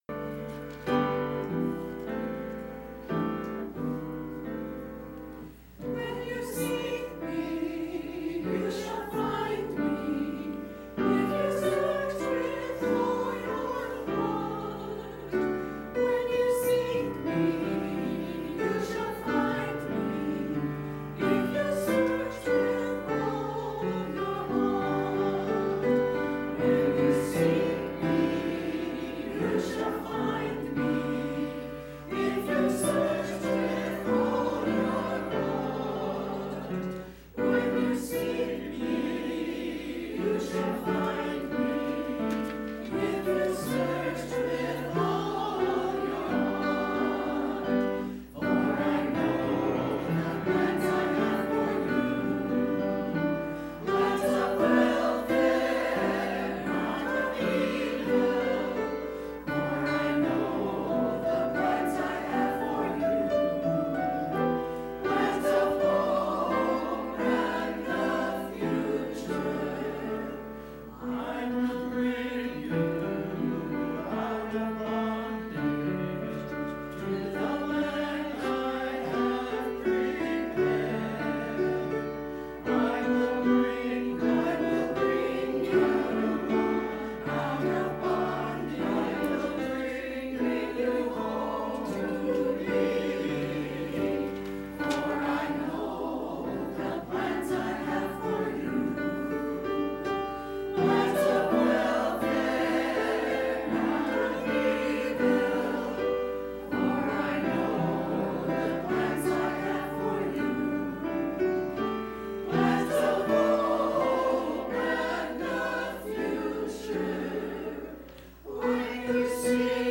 Choir – If You Search With All Your Heart 02.23.20
To hear the church choir praise God with music please click play below.